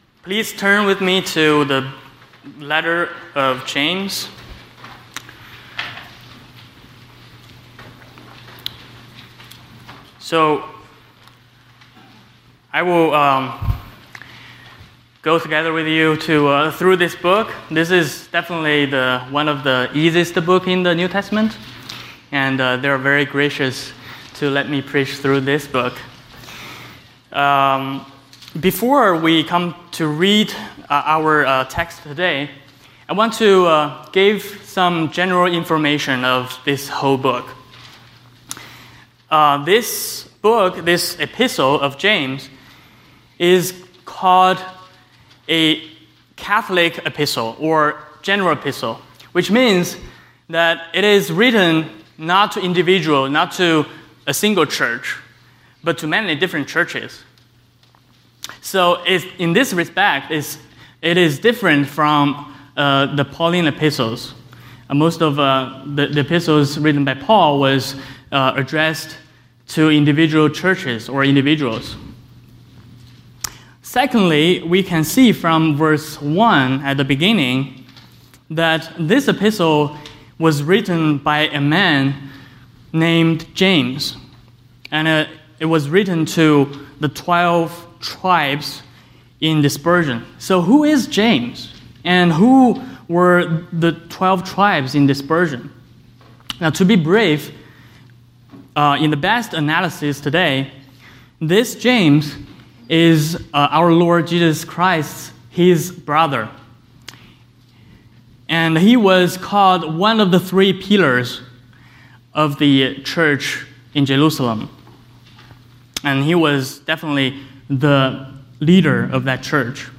James — Sermons — Christ United Reformed Church